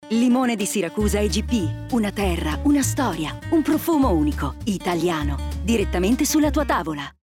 Lo spot “Campagna radio RAI nazionale 2021”
Spot_Limone-di-Siracusa-IGP.mp3